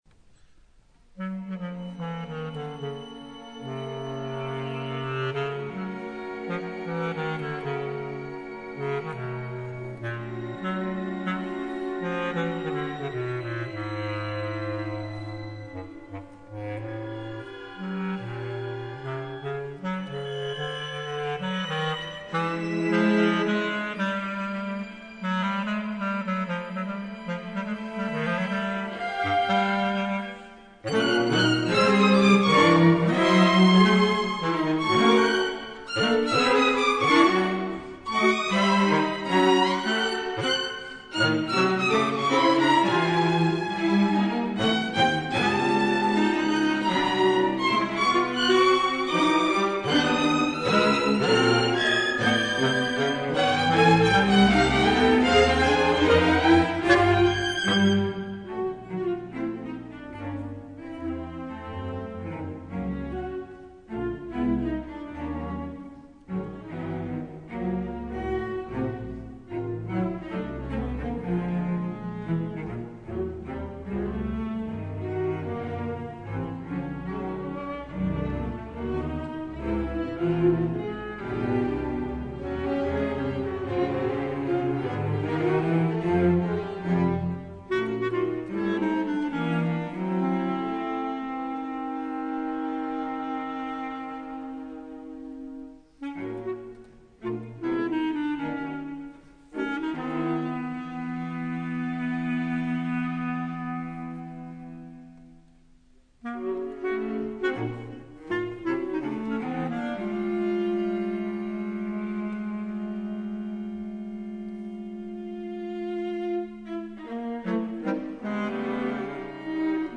version for basset horn and string orchestra
Live recording, Torino
basset horn